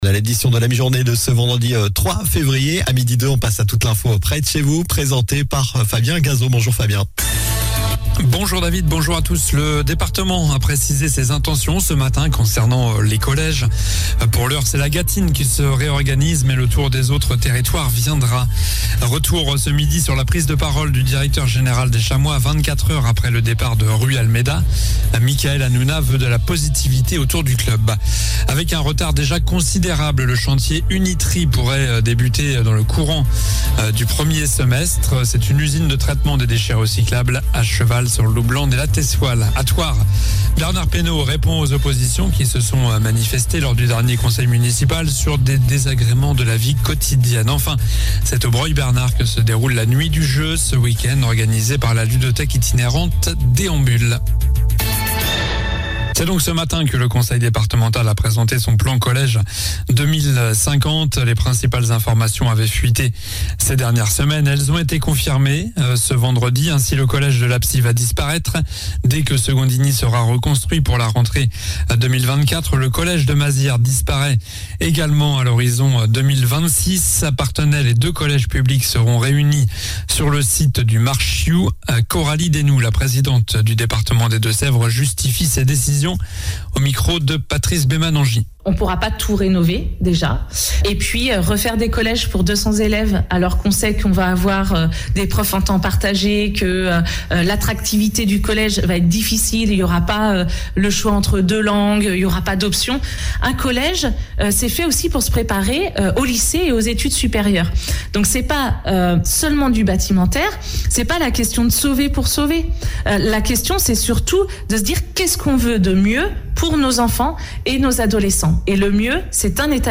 Journal du vendredi 03 février (midi)